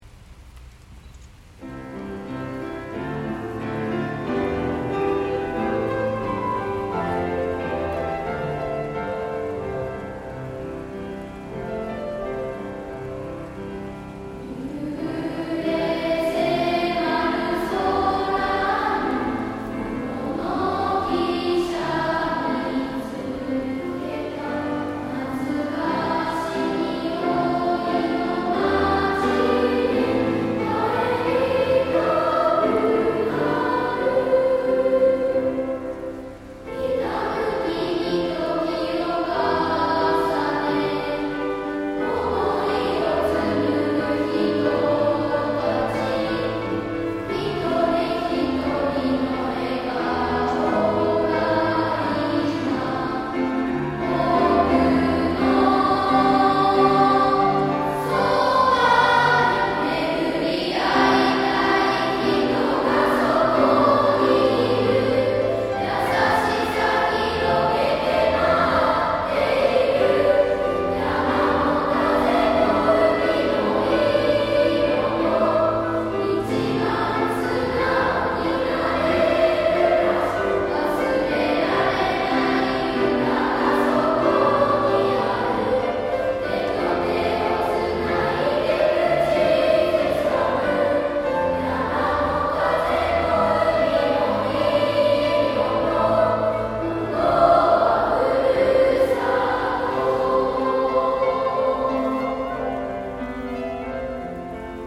昼休みに音楽集会がありました。
すばらしい歌声と演奏に聞いていた子ども達は感動していました。